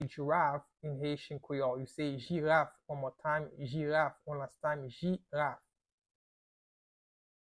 Pronunciation:
Listen to and watch “Jiraf” audio pronunciation in Haitian Creole by a native Haitian  in the video below:
How-to-say-Giraffe-in-Haitian-Creole-Jiraf-pronunciation-by-a-Haitian-teacher.mp3